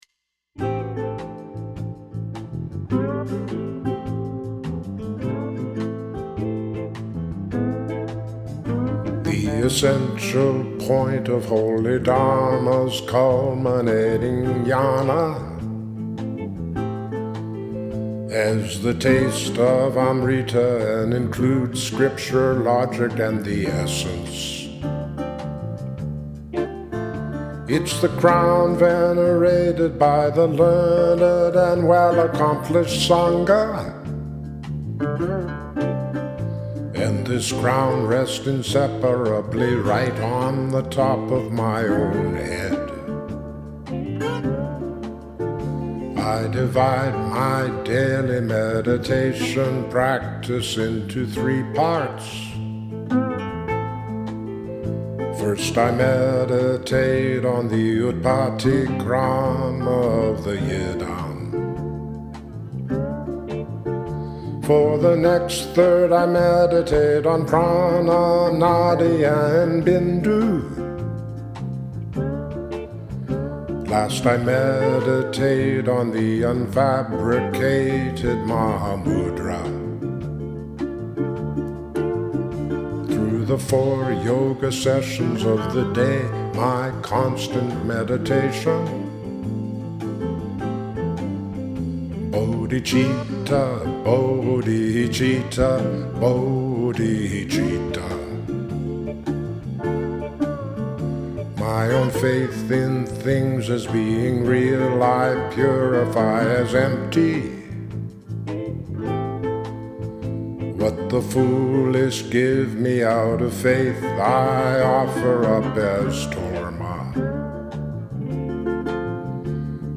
Dharma song based on the poetry of Milarepa (1052-1135)